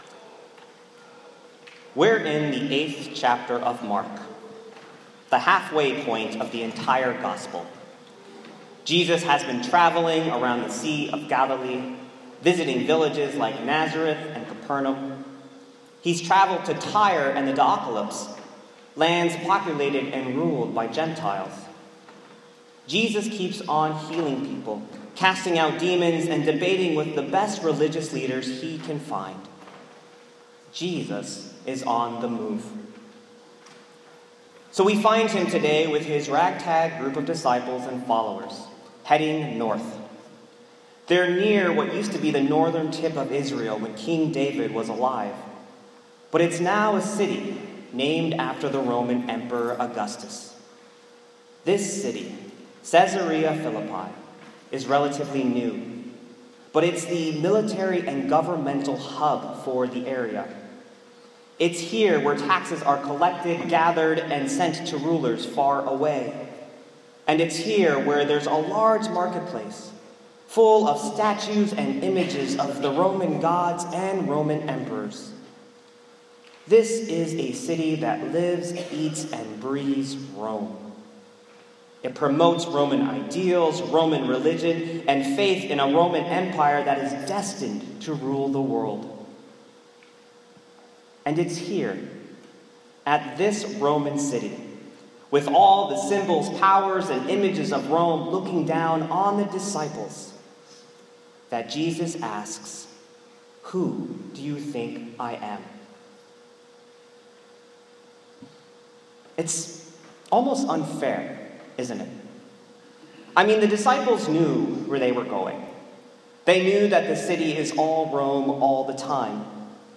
Turning Points: a sermon on Jesus, Rome, Peter, and place.